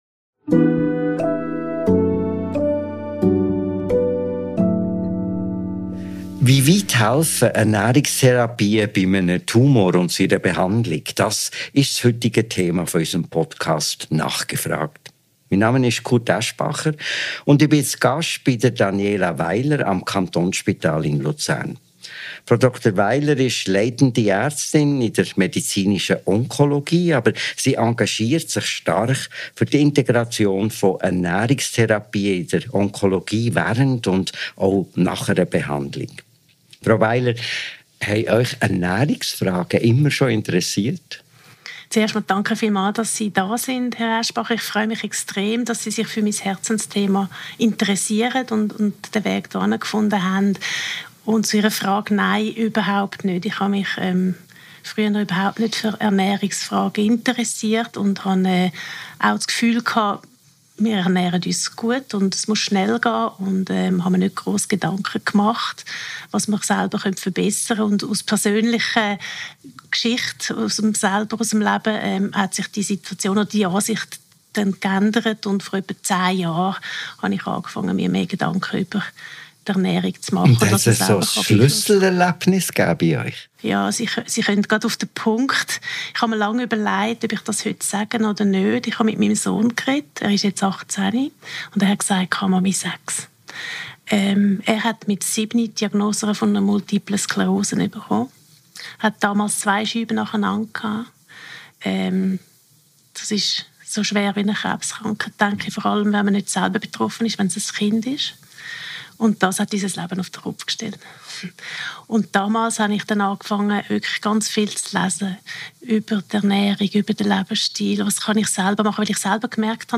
Sie erzählt, warum Vielfalt auf dem Teller so wichtig ist, weshalb schon kleine Schritte zählen – und wie Ernährung auch Hoffnung und Eigeninitiative in einer schwierigen Zeit geben kann. Hat Ihnen das Gespräch gefallen?